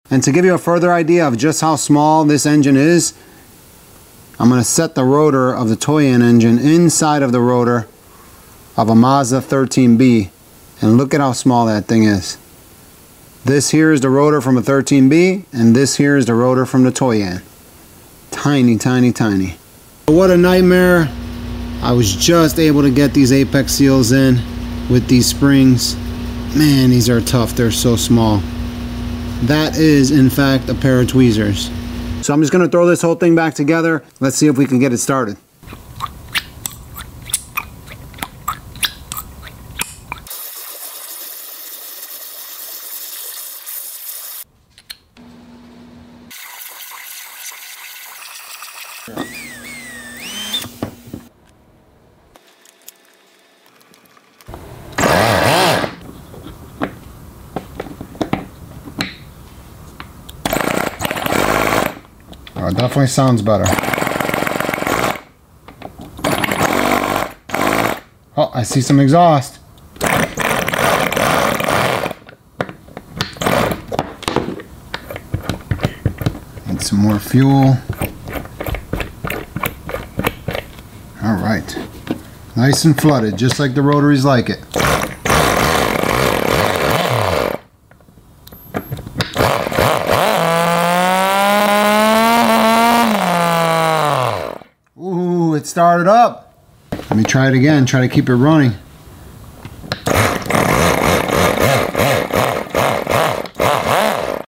Worlds Smallest Rotary Engine (30.000 RPM)